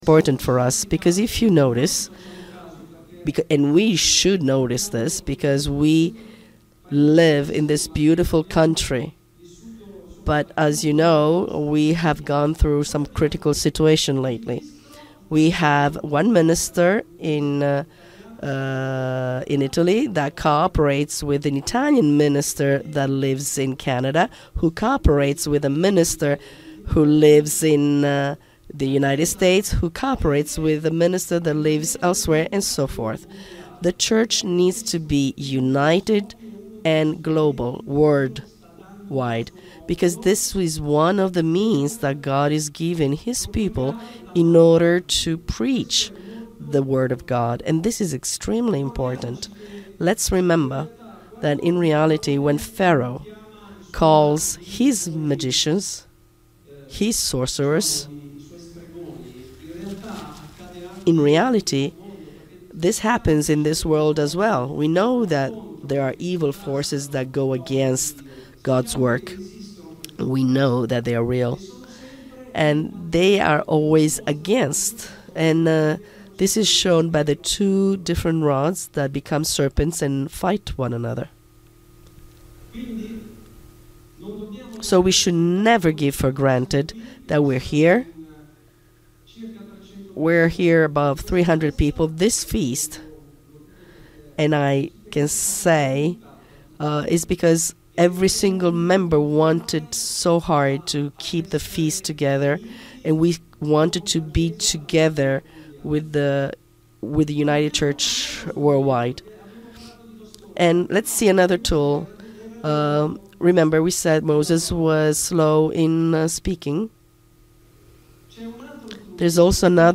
FoT 2024 Marina di Grosseto (Italy): 4th day
Sermons